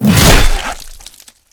flesh2.ogg